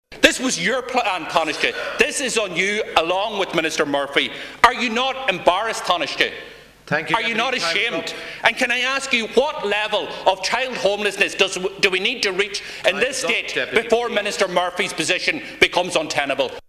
Donegal Deputy Pearse Doherty has criticised the government response during Leaders Question’s: